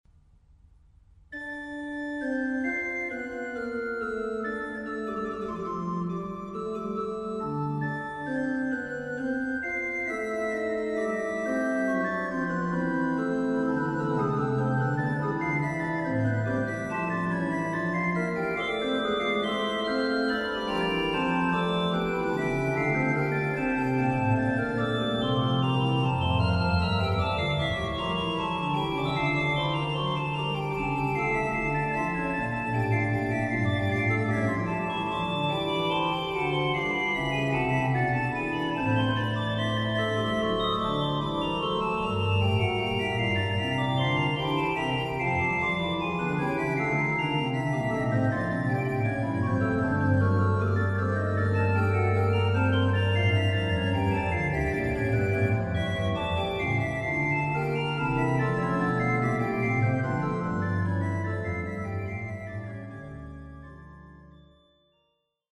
Die Kunst Der Fuge in D minor
organ
Perhaps my only complaint with this performance is occasionally the piece sounds that bit too muddled, endangering the clarity of expression and all too often sounding as though one is simply drowning in the music, though in all probability for some this would be a plus.